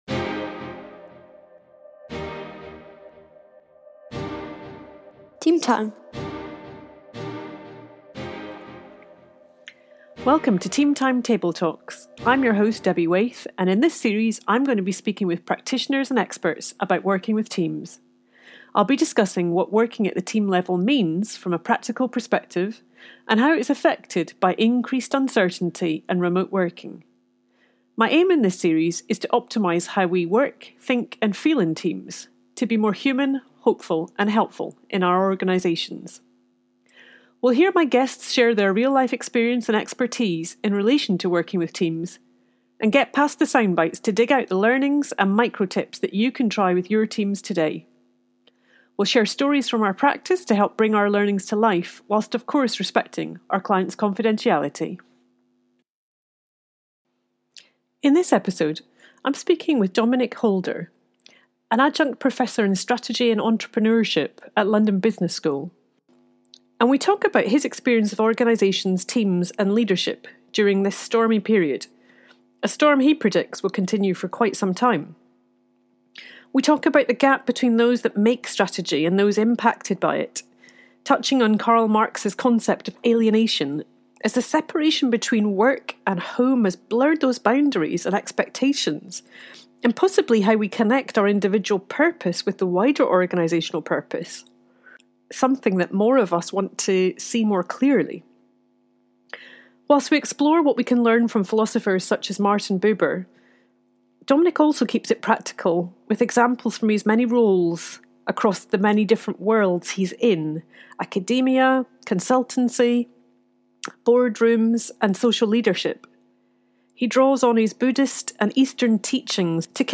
You'll hear from experienced practitioners as they share their work in this space - and challenge ourselves to consider what impact working virtually has on this work - as we explore interventions, techniques and processes that can help improve the functioning, health and performance of a team. This series aims to lift the lid on this often complex yet hidden work and share the experience of team practitioners more broadly.